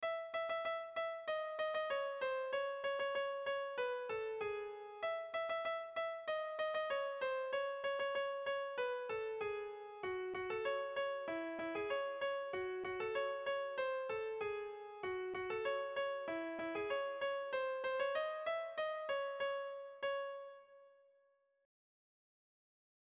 Tragikoa
Zortziko handia (hg) / Lau puntuko handia (ip)
AAB1B2